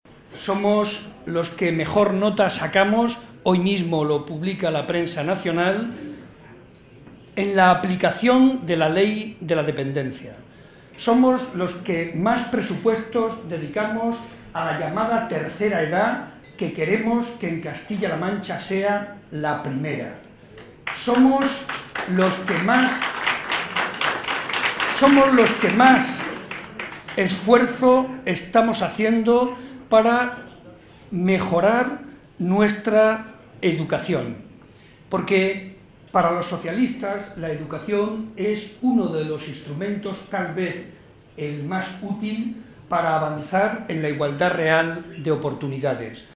Estas palabras fueron pronunciadas por Barreda en el tradicional vino de Navidad organizado por la Agrupación Socialista de Ciudad Real, momento en el que aprovechó para reivindicar el papel fundamental de la educación para lograr los valores anteriormente mencionados.
Vino navideño PSOE Ciudad Real